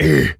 gorilla_hurt_05.wav